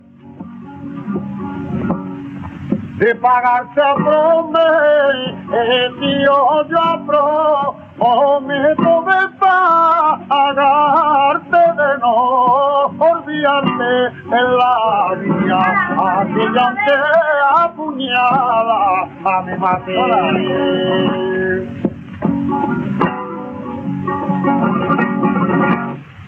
Tío José de Paula - José Cepero / Miguel Borrull
Soleá de Tío José de Paula